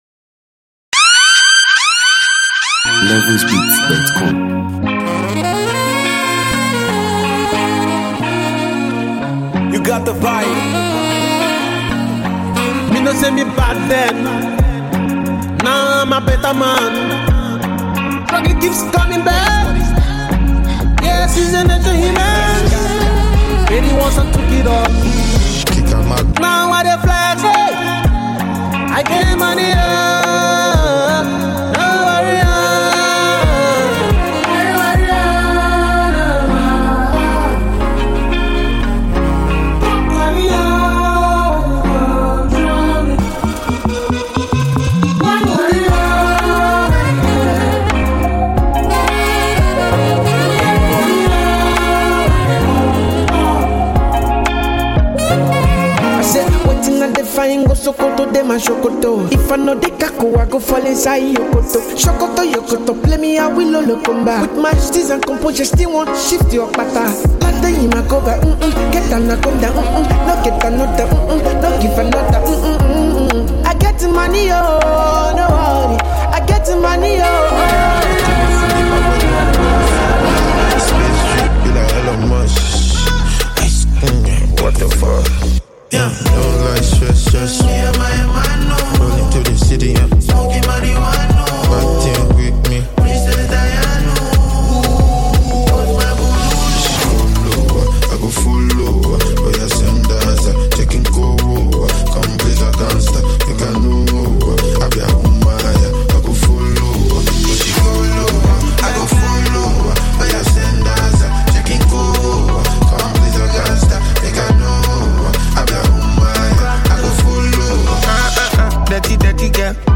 bold lyrics and confident energy
Backed by a hard-hitting beat and catchy hooks